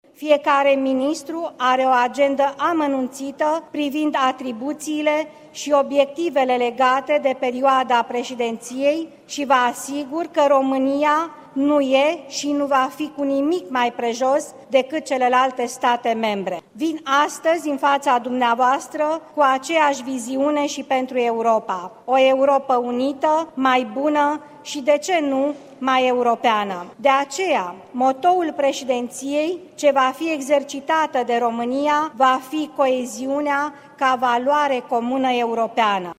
Viorica Dăncilă a prezentat, ieri, în parlament priorităţile guvernului la conducerea forului european